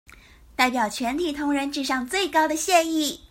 Тайваньский 430